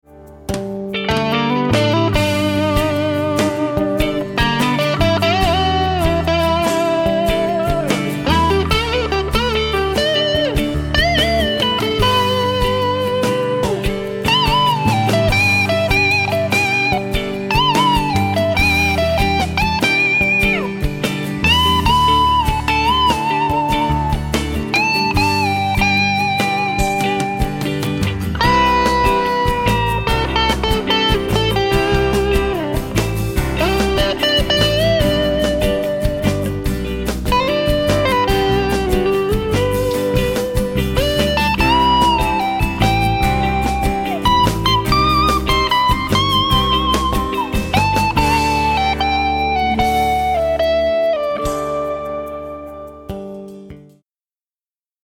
������� ��������� �����: NUX OD-3 demo 1,31 ��
nux_od-3.mp3